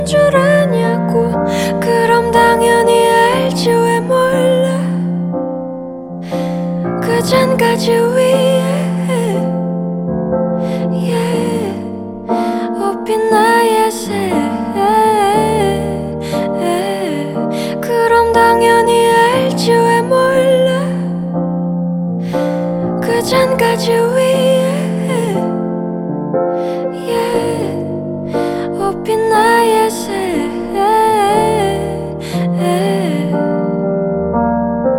Жанр: Фолк-рок
# Folk